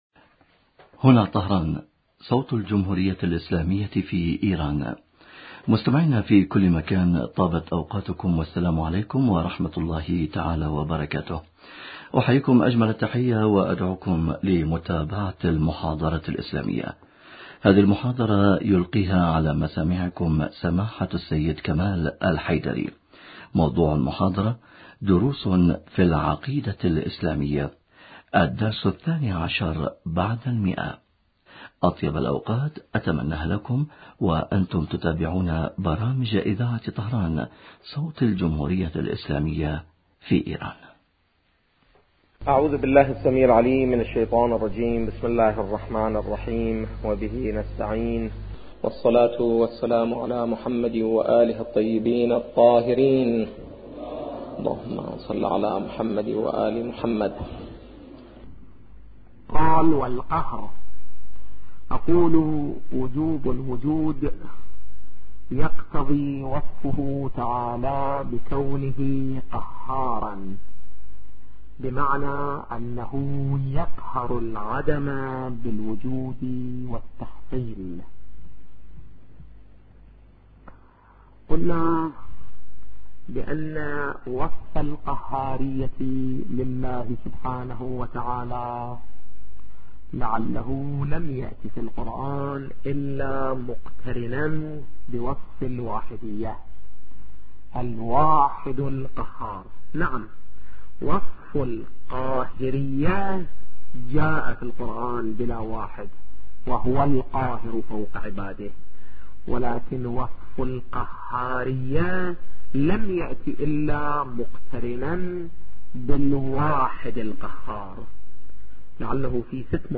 محاضرات